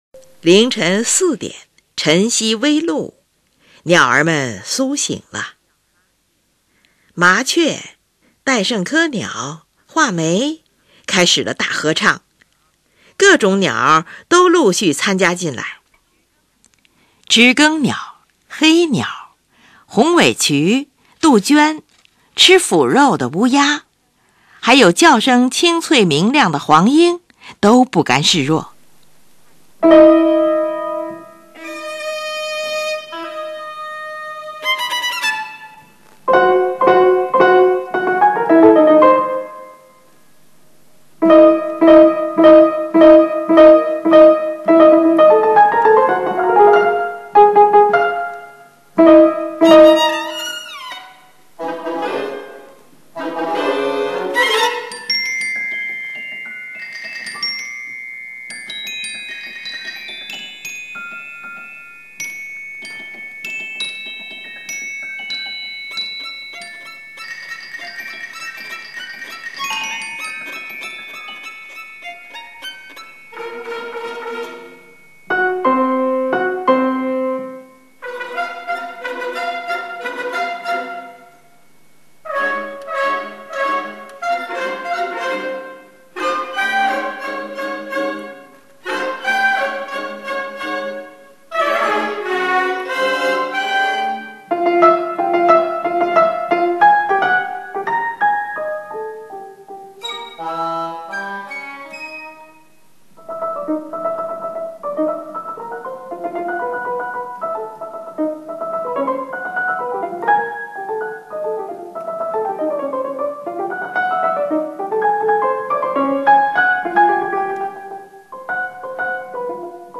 屋檐下的麻雀、戴胜科鸟的尖叫，画眉的歌唱分别在小号、木管极响亮的弦乐上出现。